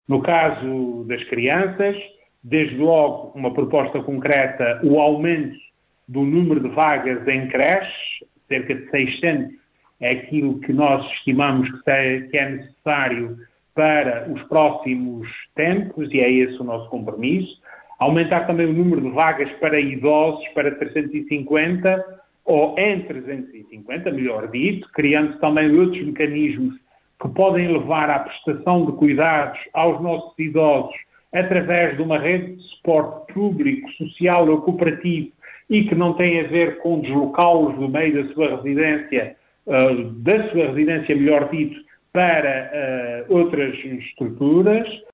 Em entrevista à Atlântida, Vasco Cordeiro diz ser imperativo ainda defender aqueles que estão numa situação de maior fragilidade, como é o caso de crianças, jovens, idosos e cidadãos portadores de deficiência.